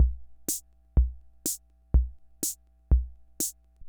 Loop18.wav